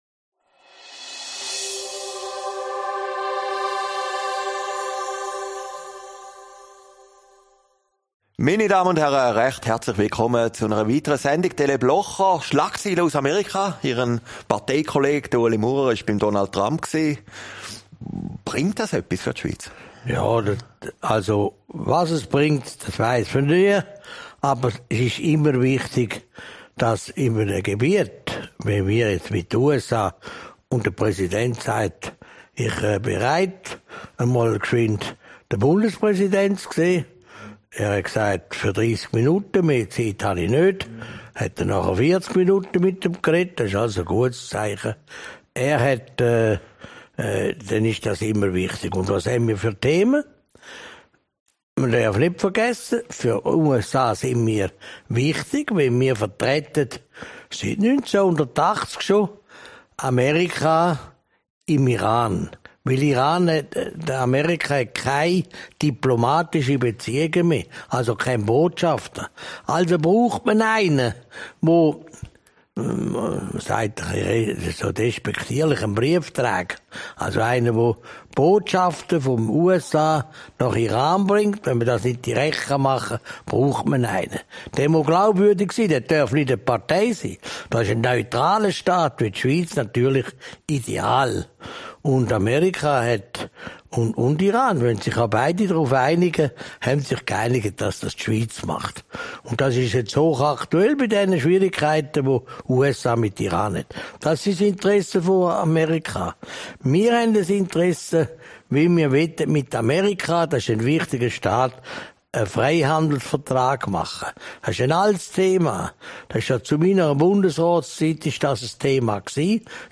Aufgezeichnet in Herrliberg, 17. Mai 2019